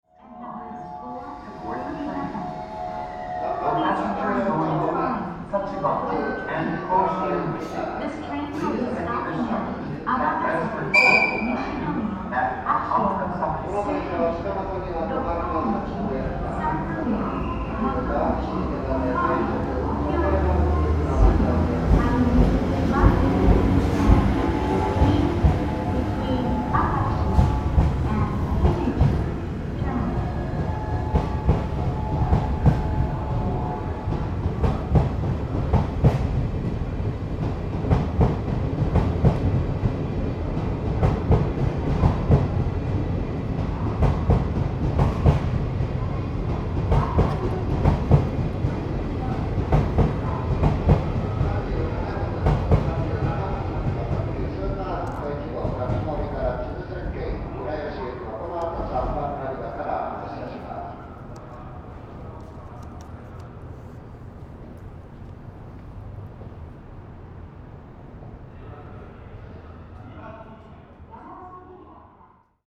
大阪駅より回送発車